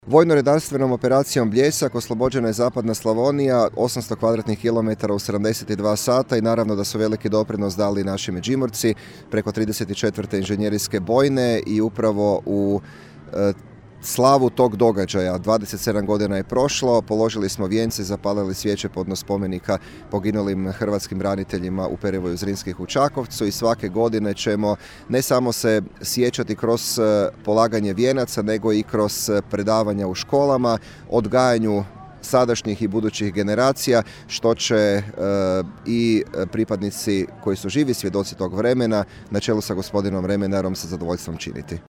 VRO Bljesak, obilježavanje u Čakovcu, 1.5.2022.
Matija Posavec, župan Međimurske županije: